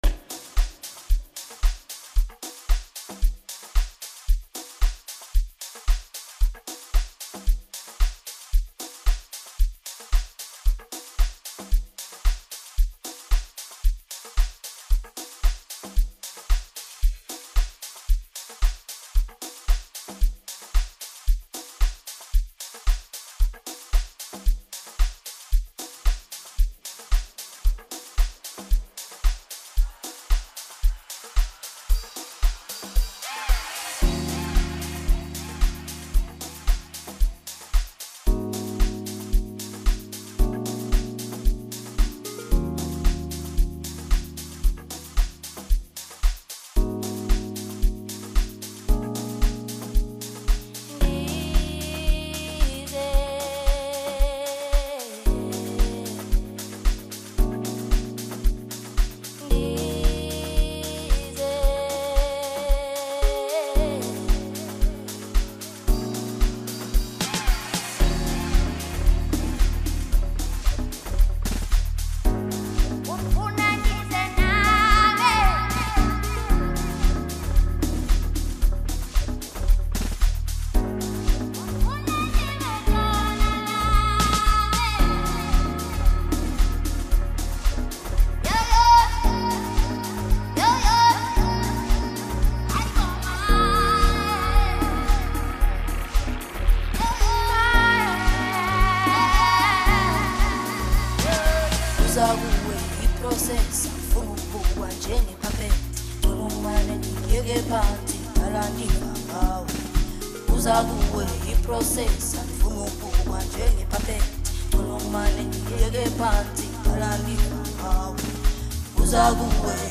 Song Genre: Amapiano.